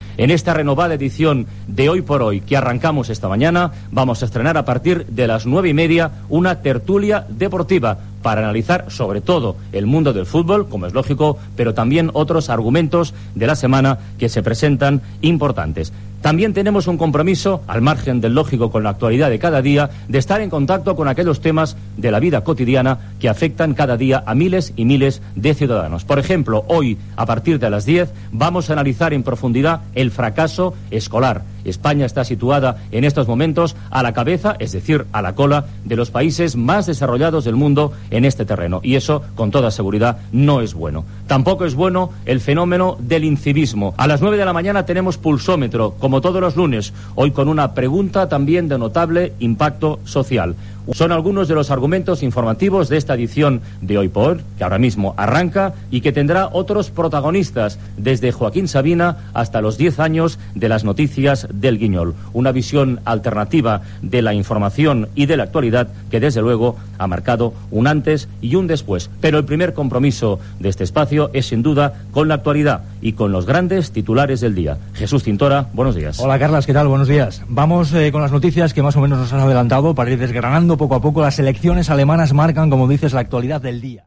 Primera edició del programa presentada per Carlos Francino.
Indicatiu de la cadena, horàries, indicatiu del programa, titular de les eleccions alemanyes.
Info-entreteniment